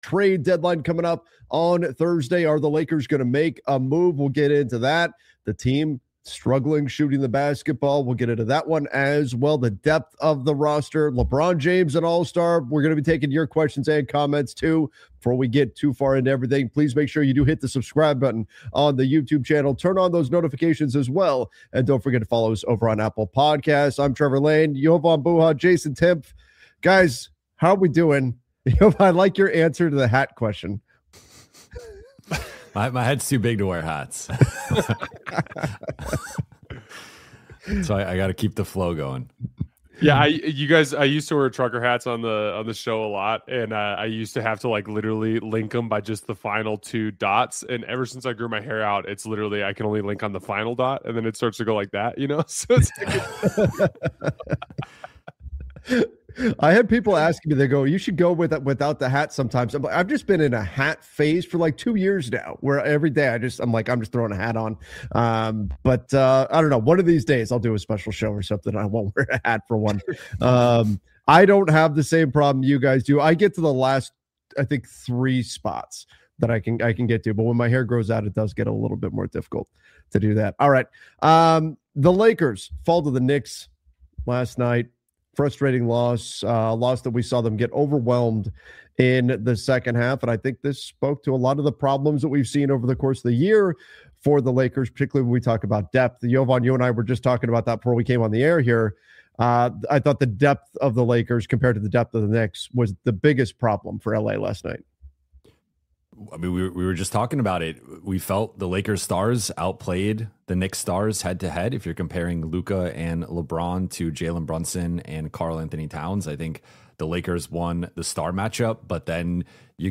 Welcome to the Lakers Collective — a weekly Los Angeles Lakers roundtable
We go live every Thursday at 10:00 AM PT with sharp analysis, smart X’s-and-O’s talk, and honest conversations about all things Lakers — from game breakdowns and player development to cap moves and playoff paths.